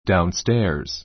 daunstéə r z ダウン ス テ ア ズ